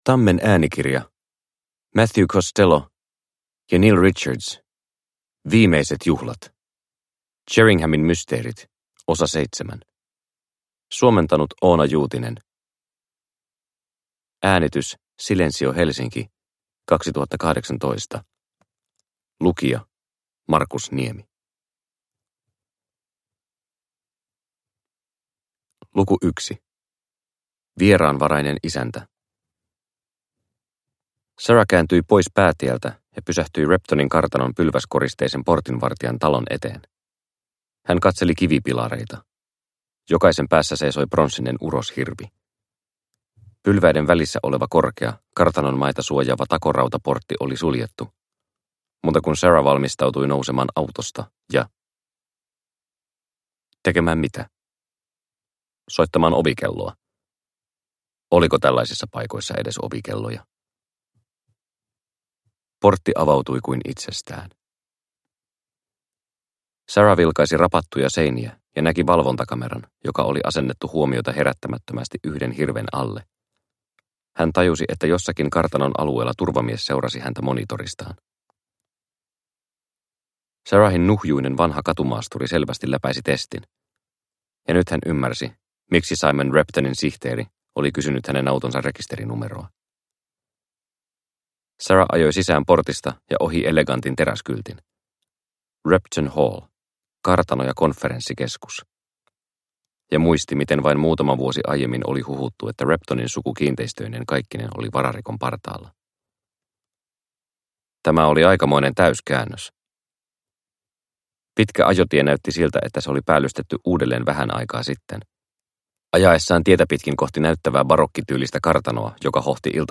Viimeiset juhlat – Ljudbok – Laddas ner